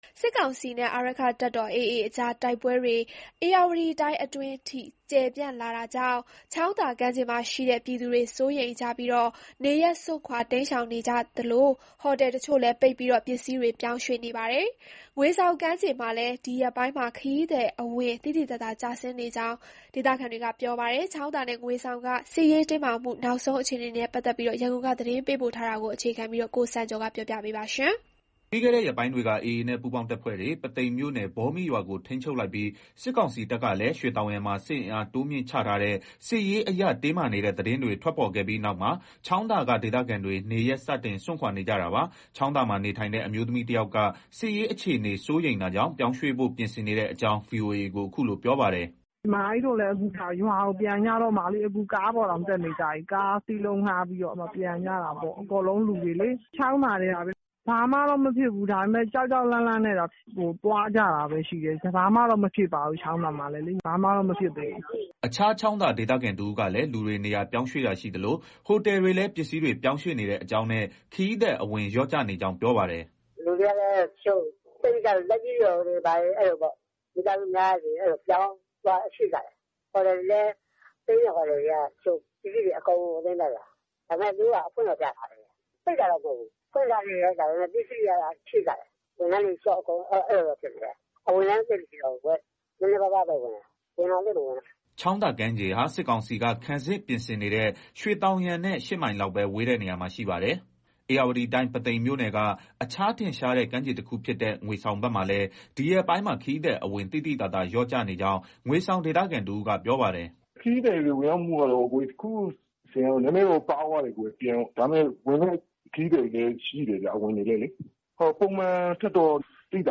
ချောင်းသာမှာ နေထိုင်တဲ့ အမျိုးသမီးတယောက်က စစ်ရေးအခြေအနေ စိုးရိမ်တာကြောင့် ပြောင်းရွှေ့ဖို့ ပြင်ဆင်နေတဲ့ ကြောင်း VOA ကို အခုလို ပြောပါတယ်။
အခြားချောင်းသာ ဒေသခံတဦးကလည်း လူတွေ နေရာပြောင်းရွှေ့တာရှိသလို ဟိုတယ်တွေလည်း ပစ္စည်းတွေ ပြောင်းရွှေ့နေတဲ့အကြောင်းနဲ့ ခရီးသည် အဝင် လျော့ကျနေကြောင်း ပြောပါတယ်။